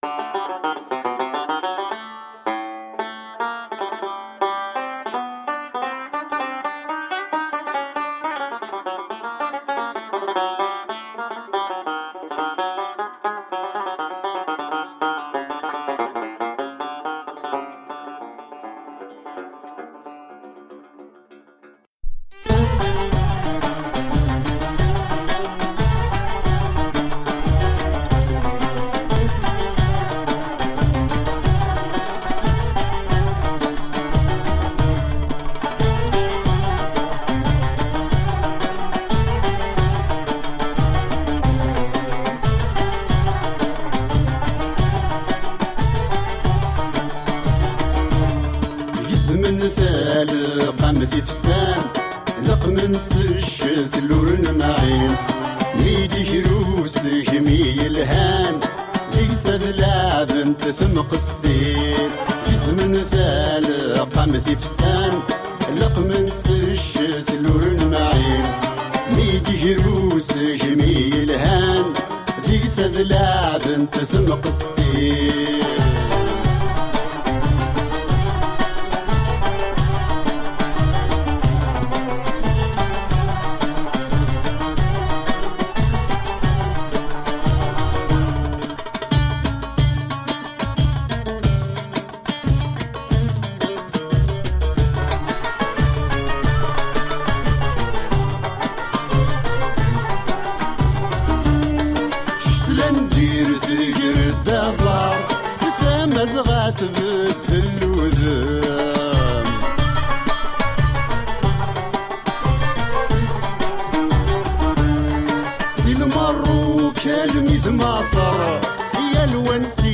Tagged as: World, Other, World Influenced, Arabic influenced